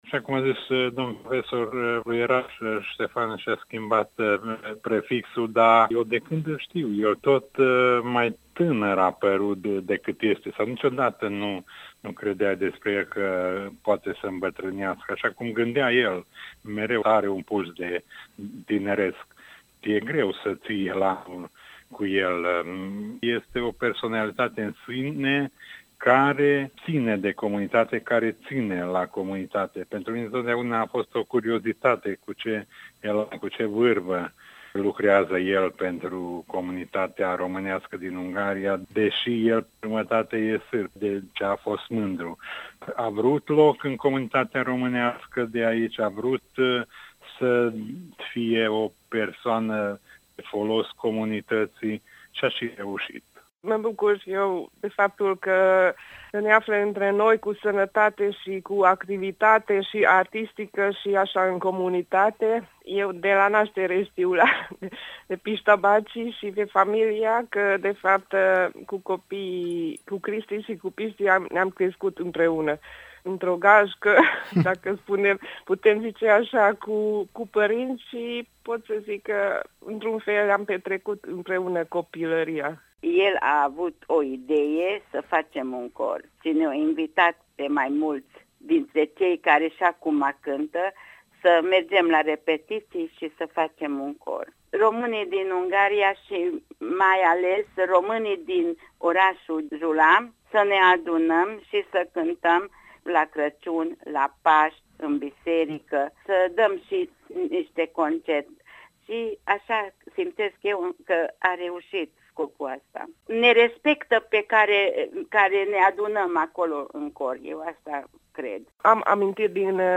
A sunat prieteni și apropiați să povestească de ce îl apreciază.